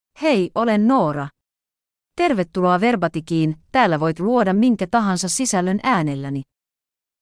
Noora — Female Finnish AI voice
Noora is a female AI voice for Finnish (Finland).
Voice sample
Listen to Noora's female Finnish voice.
Female
Noora delivers clear pronunciation with authentic Finland Finnish intonation, making your content sound professionally produced.